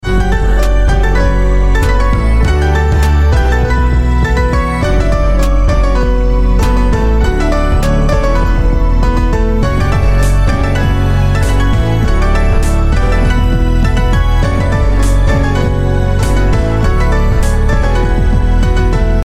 christmas rap song